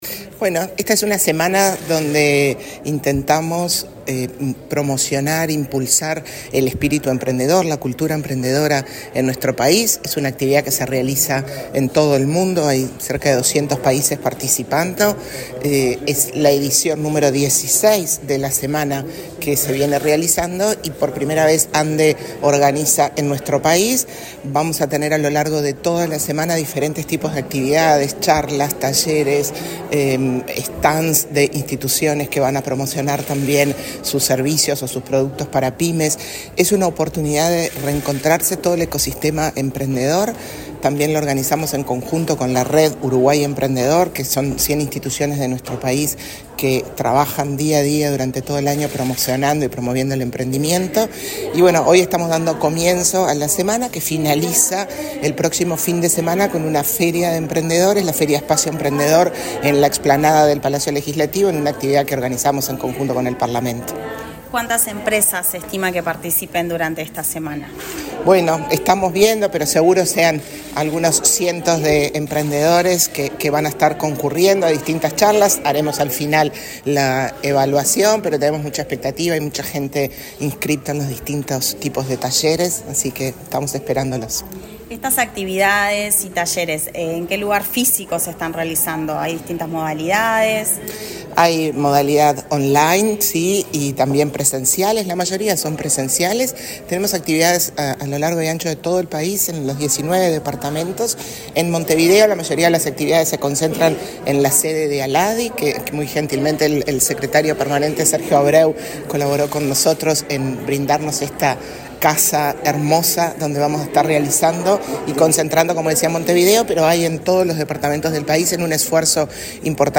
Declaraciones de la presidenta de ANDE, Carmen Sánchez
Antes, dialogó con la prensa.